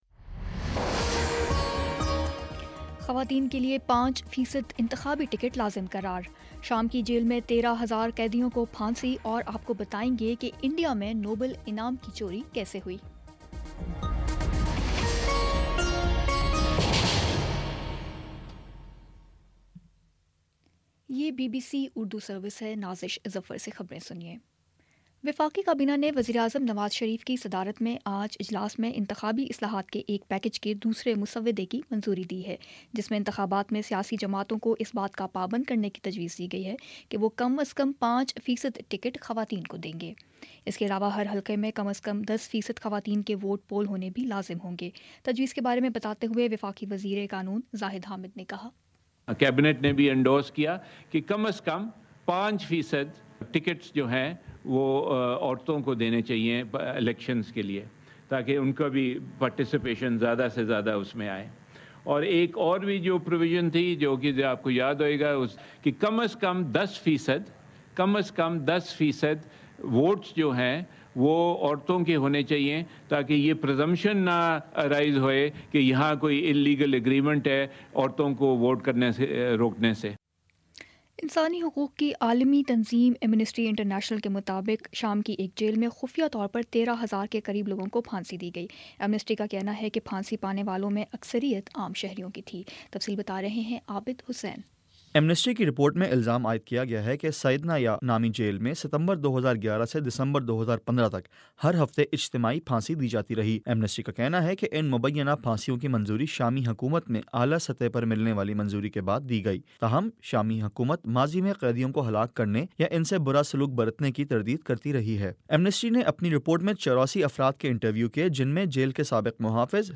فروری 07 : شام پانچ بجے کا نیوز بُلیٹن